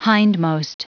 Prononciation du mot hindmost en anglais (fichier audio)
Prononciation du mot : hindmost